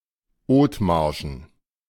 Othmarschen (German pronunciation: [ˈoːtˌmaːɐ̯ʃn̩]